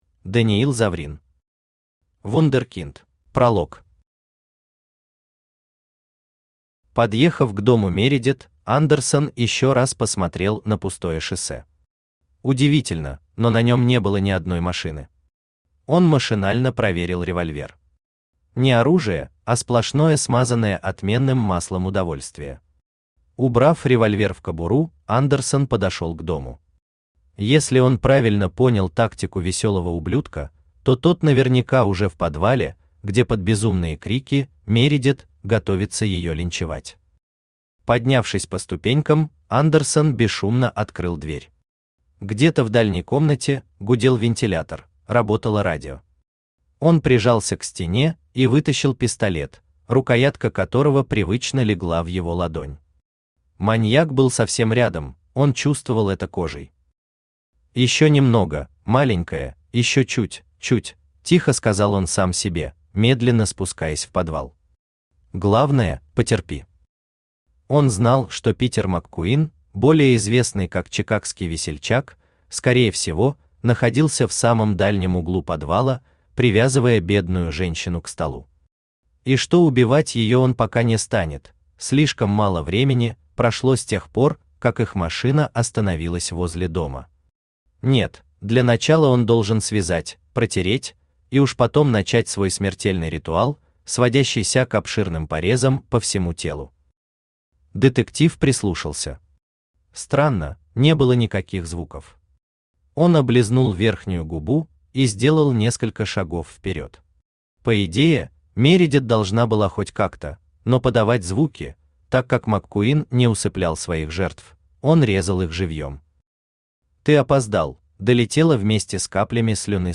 Аудиокнига Вундеркинд | Библиотека аудиокниг
Aудиокнига Вундеркинд Автор Даниил Заврин Читает аудиокнигу Авточтец ЛитРес.